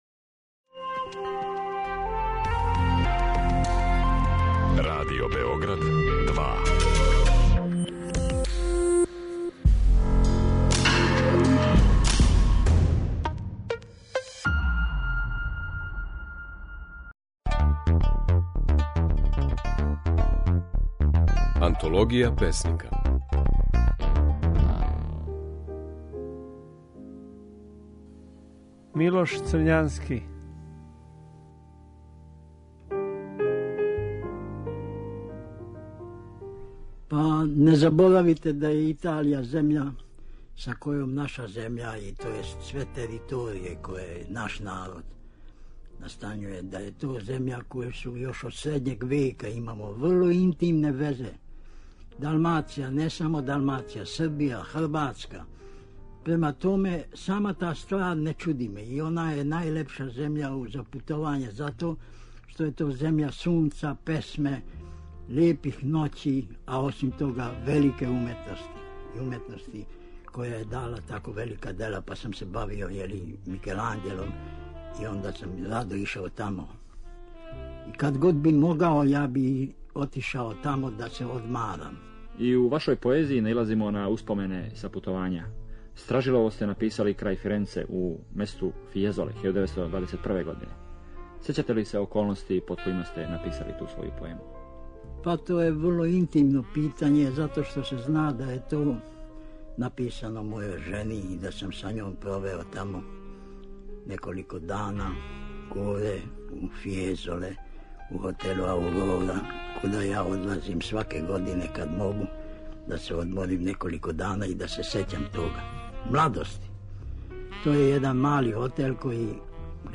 У емисији Антологија песника, можете чути како је своје стихове говорио Милош Црњански.